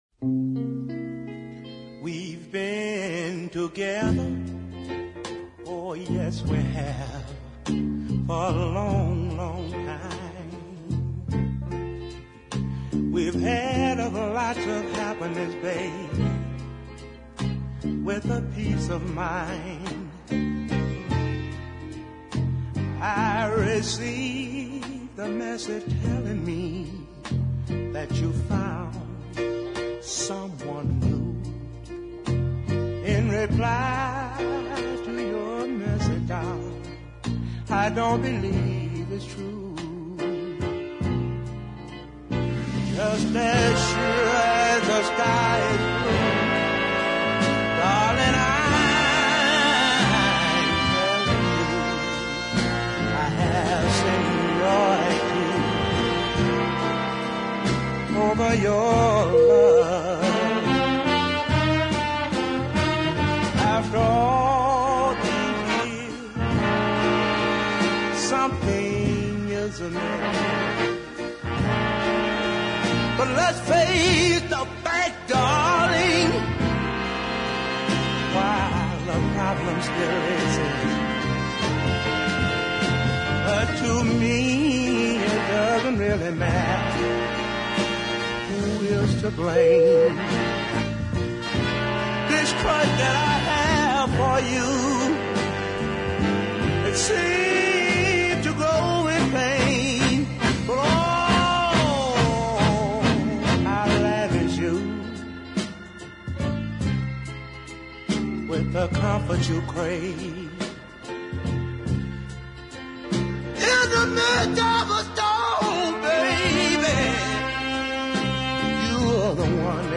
a really first class deep ballad